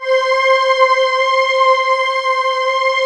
Index of /90_sSampleCDs/USB Soundscan vol.28 - Choir Acoustic & Synth [AKAI] 1CD/Partition D/23-SOMEVOICE